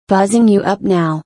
buzzing_up_boosted.protected.mp3